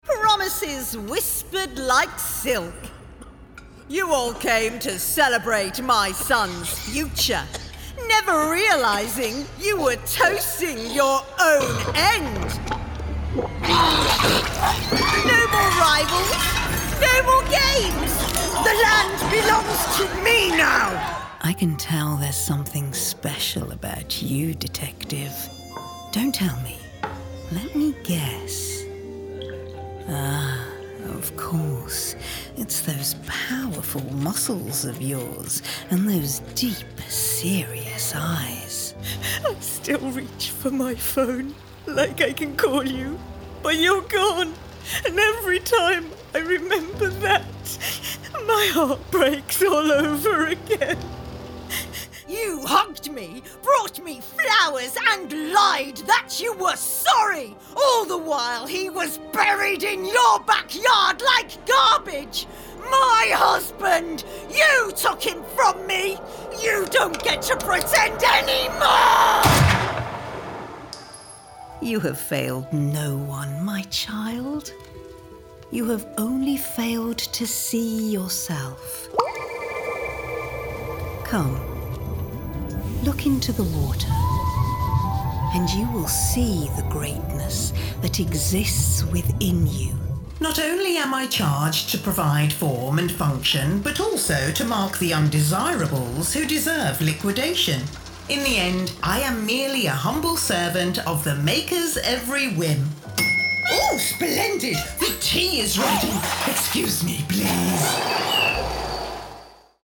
Inglés (Reino Unido)
Videojuegos
MacBook Air, interfaz Focusrite Scarlett de tercera generación y micrófono Rode, auriculares cerrados, antipop y cabina vocal acondicionada
Mezzosoprano
Voces de personajes